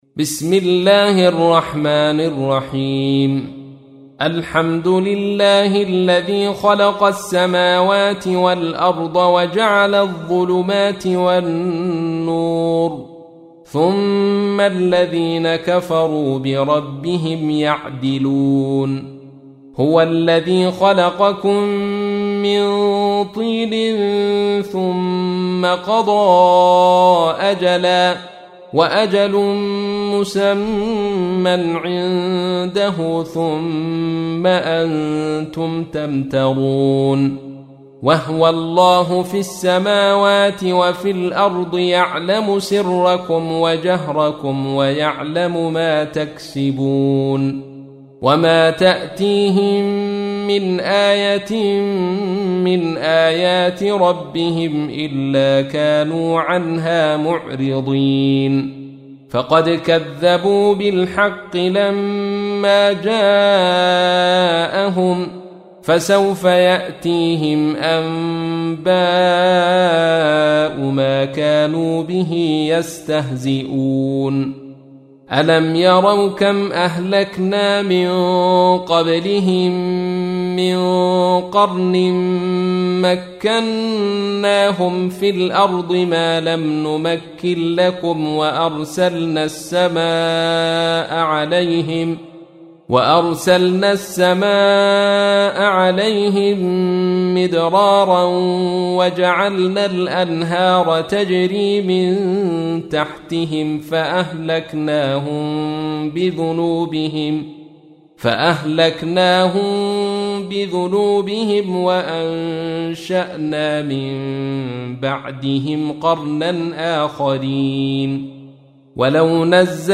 تحميل : 6. سورة الأنعام / القارئ عبد الرشيد صوفي / القرآن الكريم / موقع يا حسين